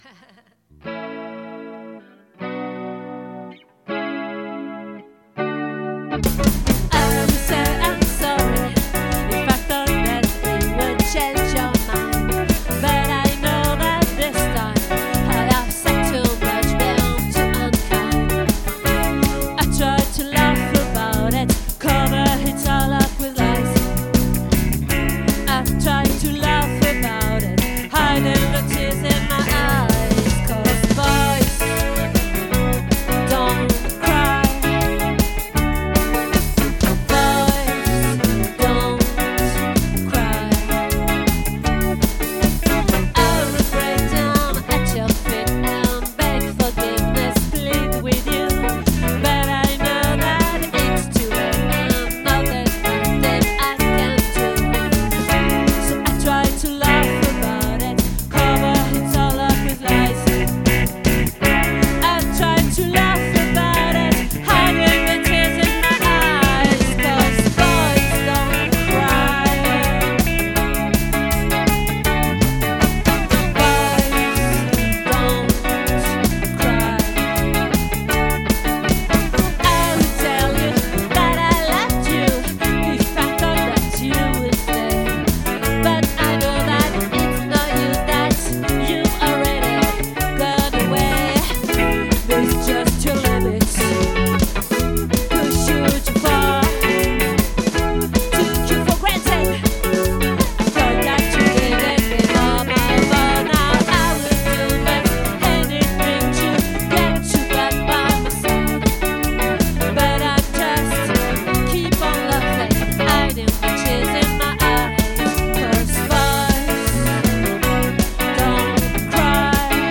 🏠 Accueil Repetitions Records_2024_01_24